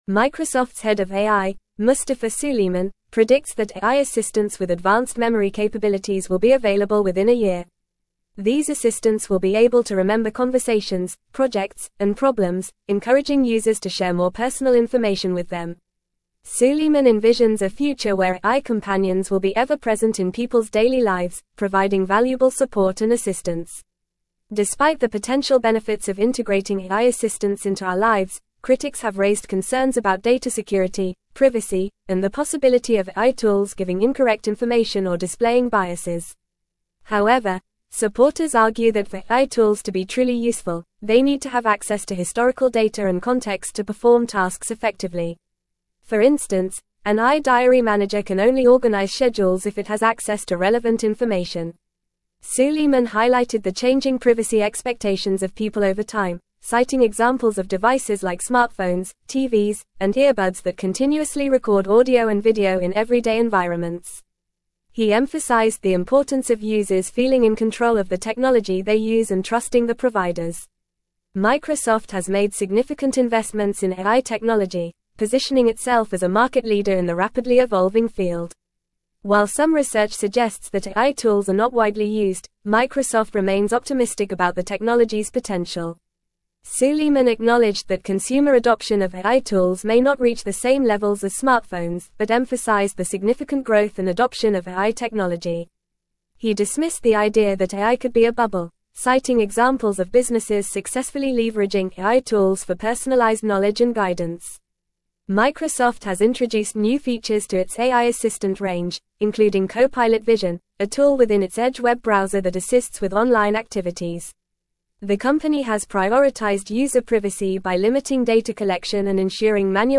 Fast
English-Newsroom-Advanced-FAST-Reading-Microsofts-Head-of-AI-Predicts-Advanced-Memory-Assistants.mp3